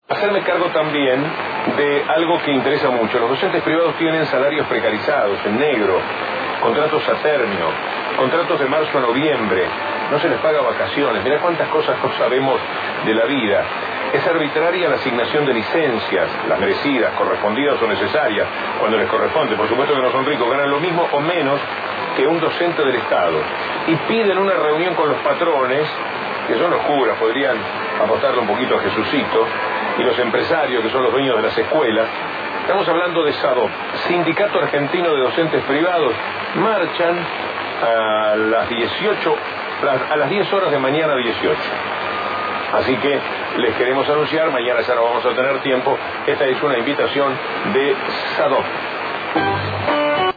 El programa de radio que conduce el periodista Víctor Hugo Morales, "La Mañana" por Continental AM 590, informa sobre la marcha que realizará el Sindicato el jueves 18 de octubre a las Cámaras Patronales.